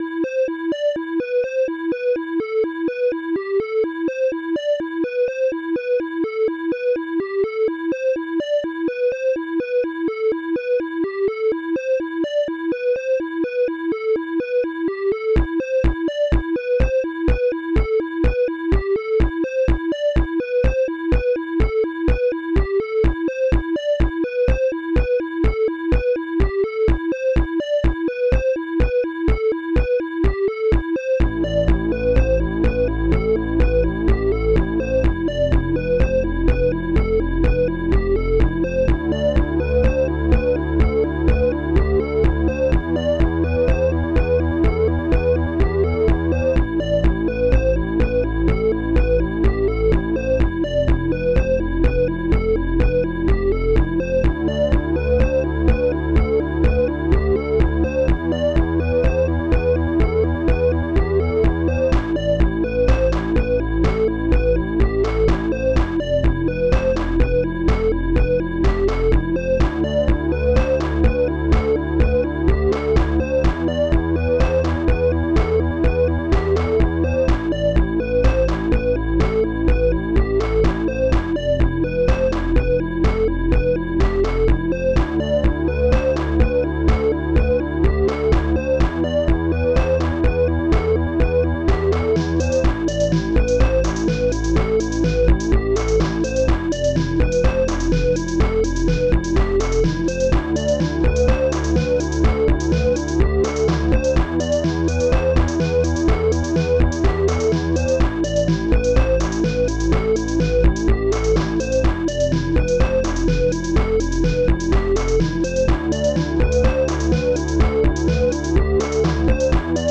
Protracker Module
Instruments sinecz bassdrum1 analogstring claps1 snare3 hihat2 strings3 electom woodblock popbass strings2